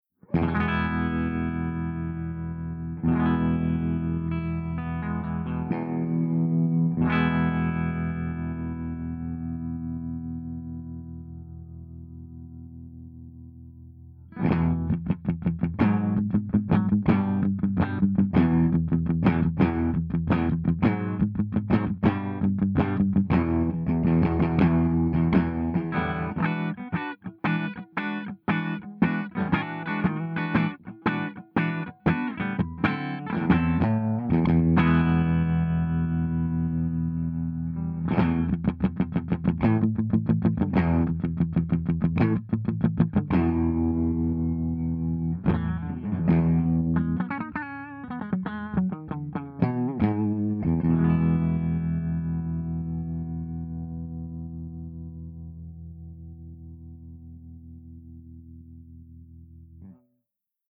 072_FENDERSUPERREVERB_WARMTREMOLO_HB.mp3